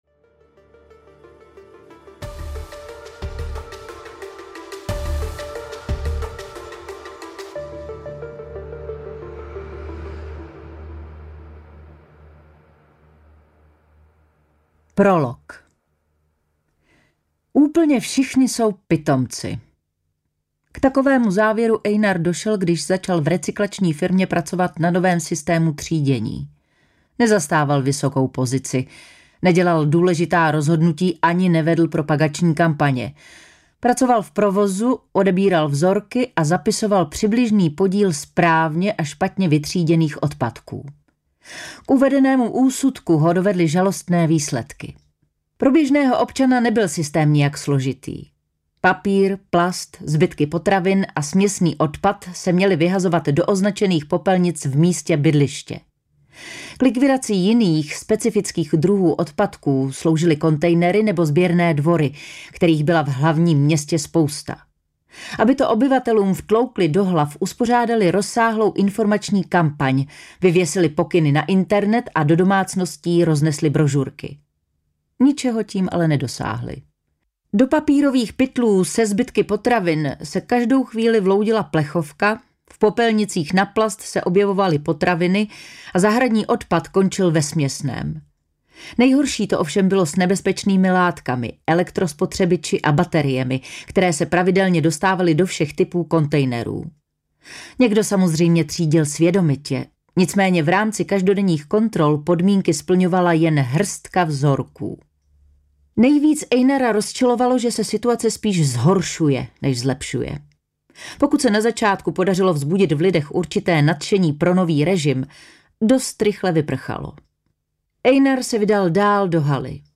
Hlubiny audiokniha
Ukázka z knihy
• InterpretKlára Cibulková